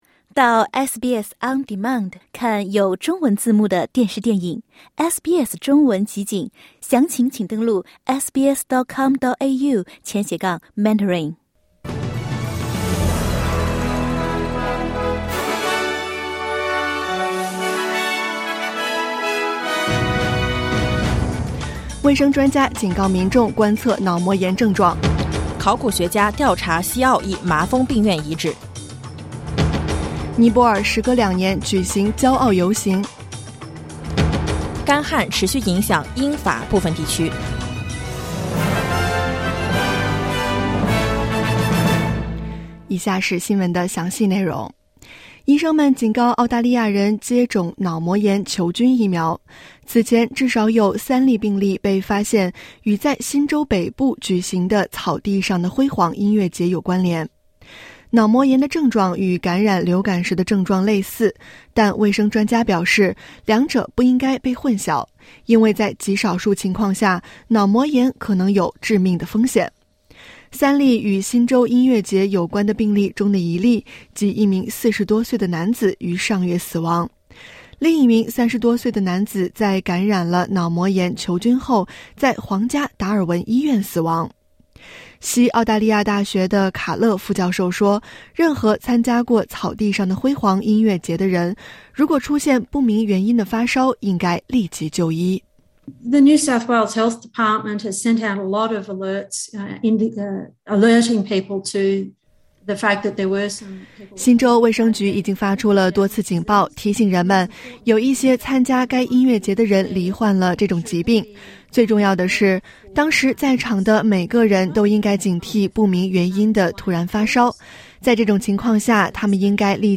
SBS早新闻（2022年8月14日）